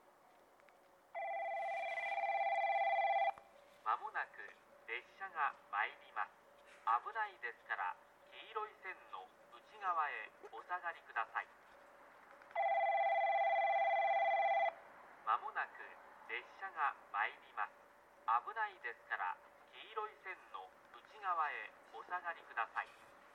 この駅では接近放送が設置されています。
１番のりば日豊本線
接近放送普通　西都城行き接近放送です。